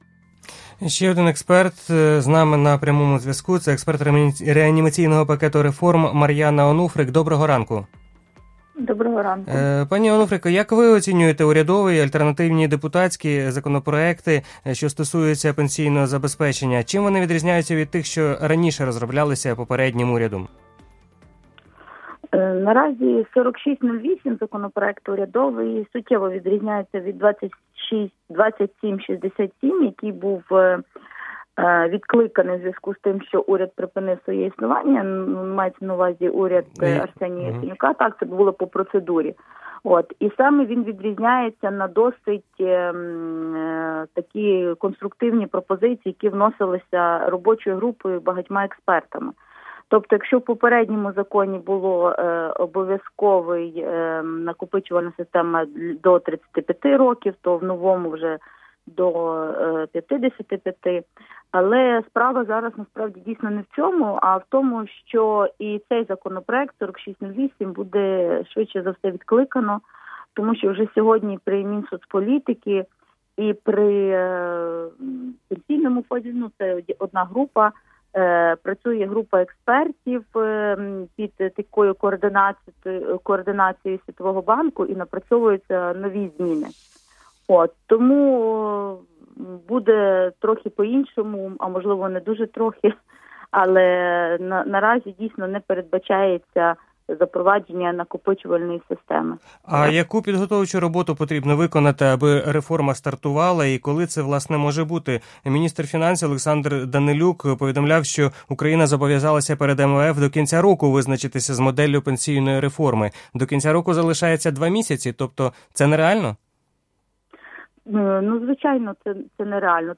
відео Радіо Свобода